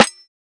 SNARE.25.NEPT.wav